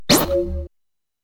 menuhit.wav